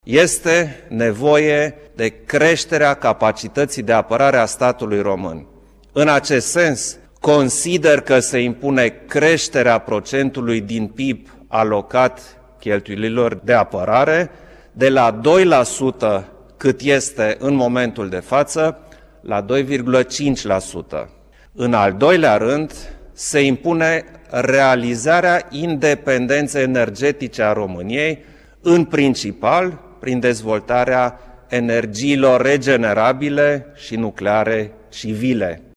Președintele Klaus Iohannis – declarații la finalul ședinței CSAT [AUDIO]